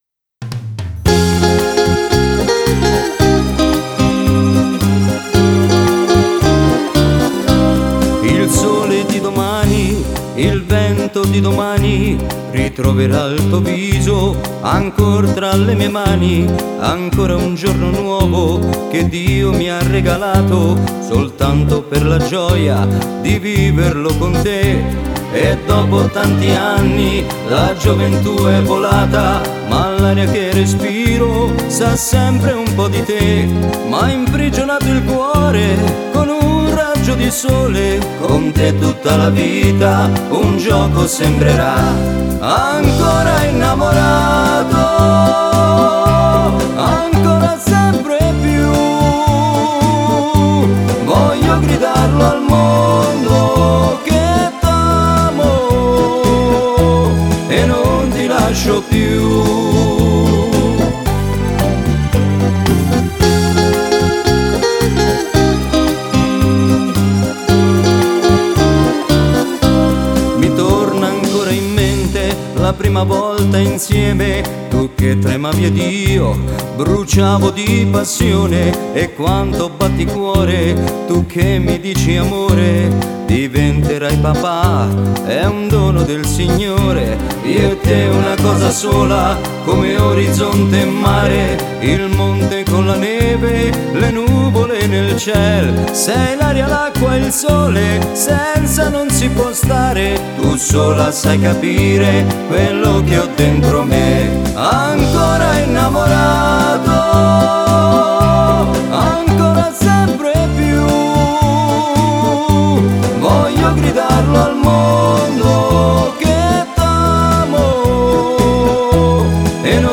Rumba
e 12 ballabili per Fisarmonica solista